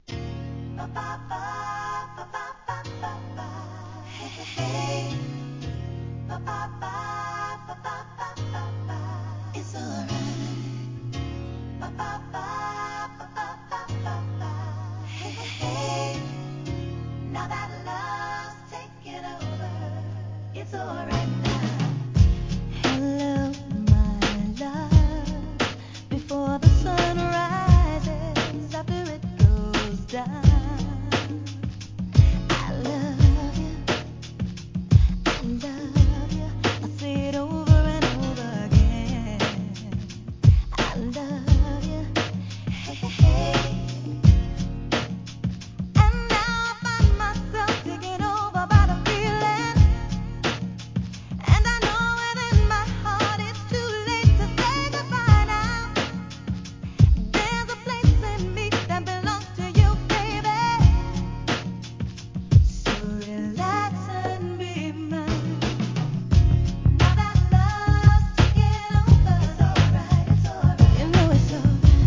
HIP HOP/R&B
お洒落で、甘い歌声に耳に優しく残るさびが気持ちいいメロウナンバー!!